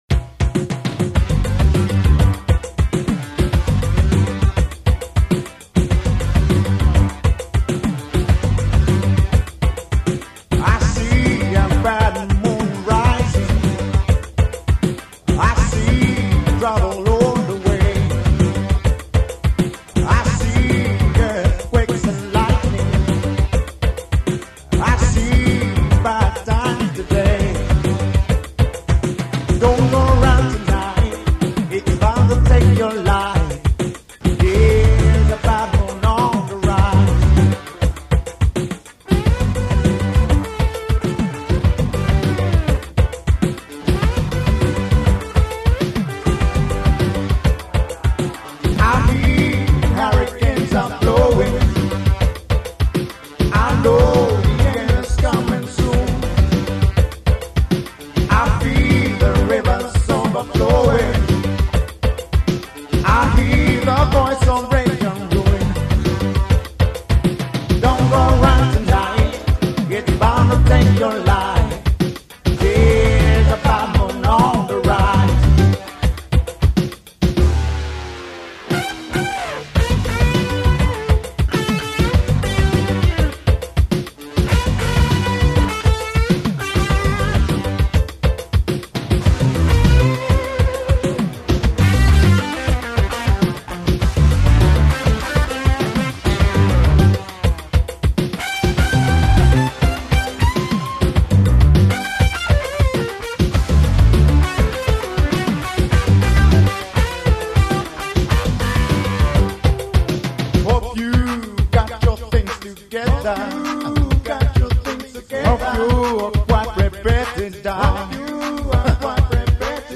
Slide Guitar cover
Roland D110